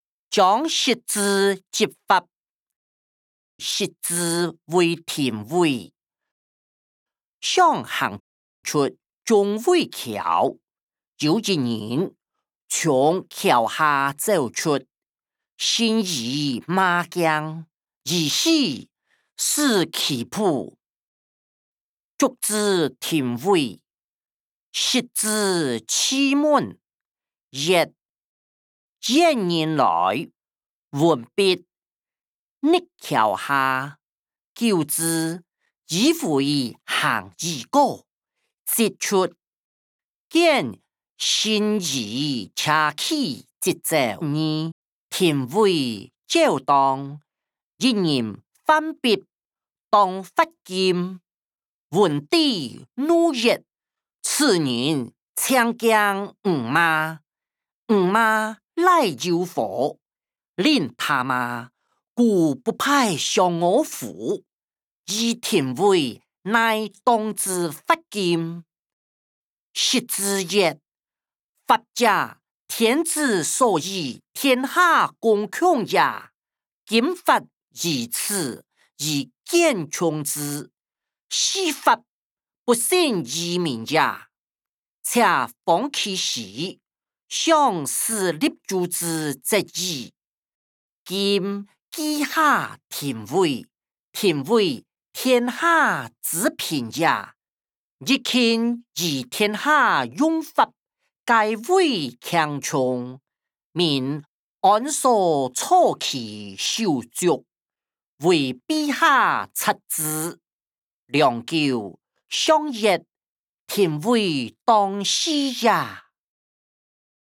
歷代散文-張釋之執法音檔(大埔腔)